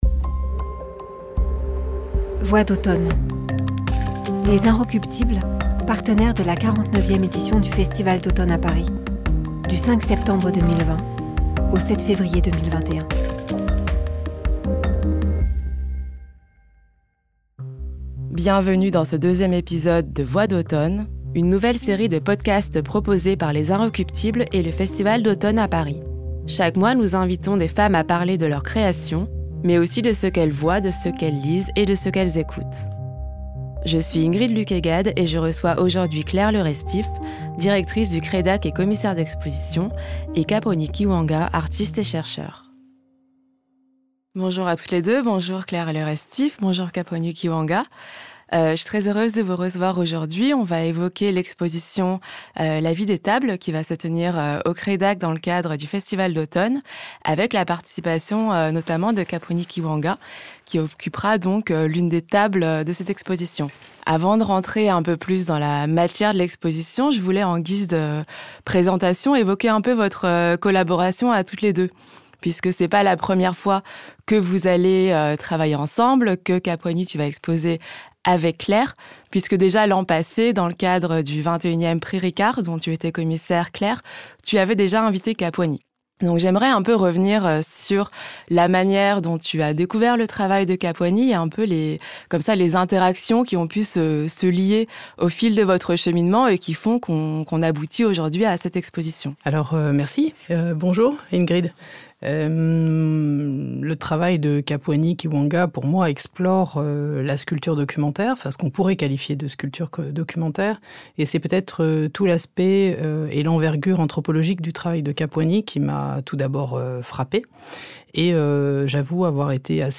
Interview croisée